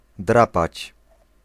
Ääntäminen
US : IPA : [skɹætʃ]